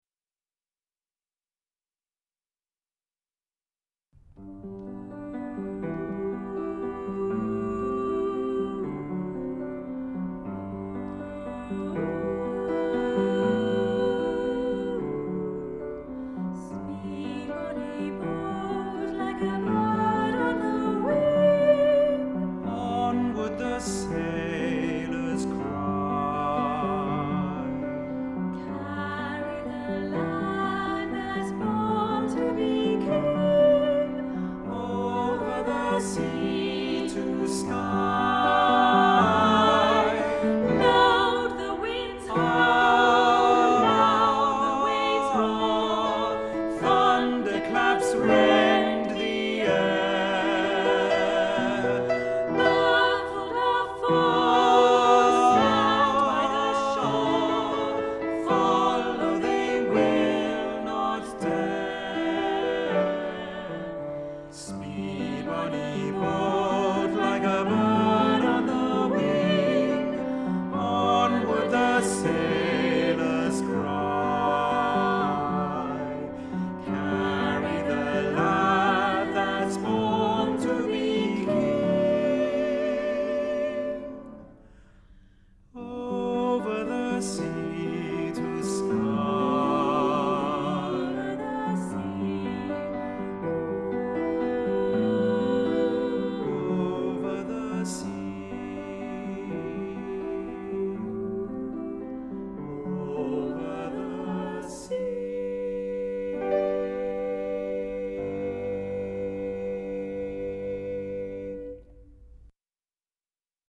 Voicing: Choir